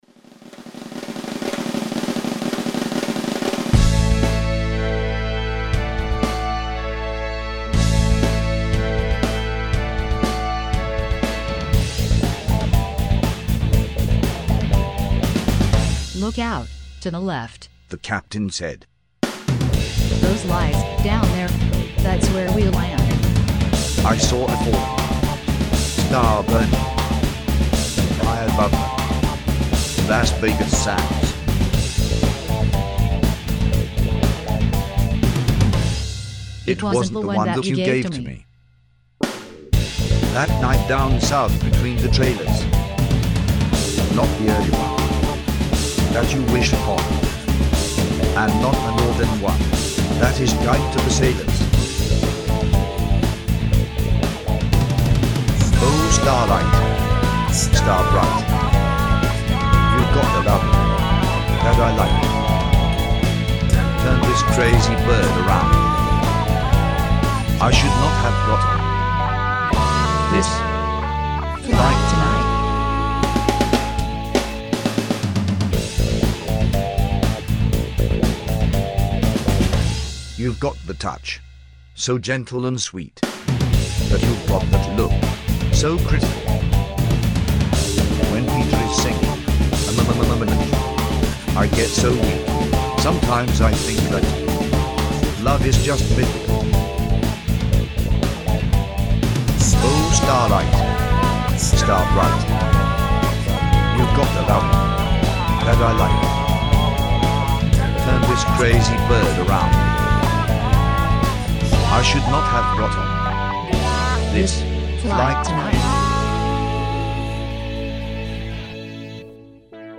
with a hint of Afro 6/8 beat thrown in too!